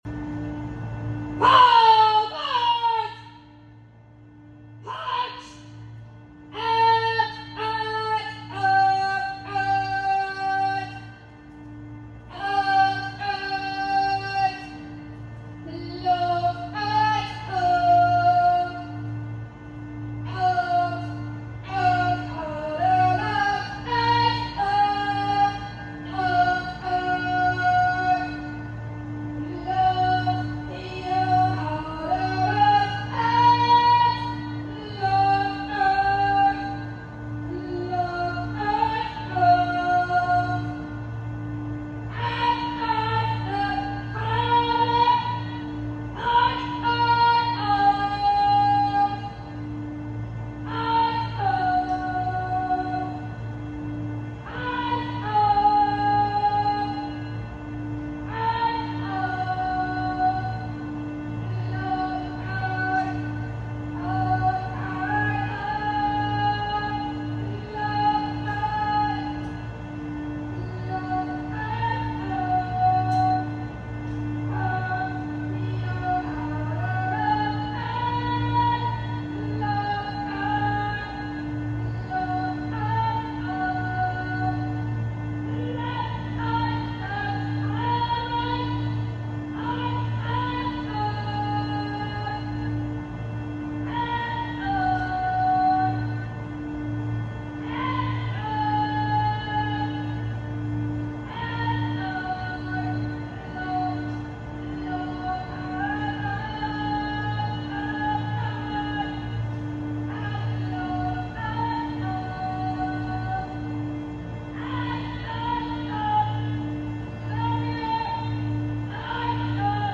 Pardon the shakes in my voice and lapses in cadence timing… the emotions were flowing and it’s hard to sing thru tears.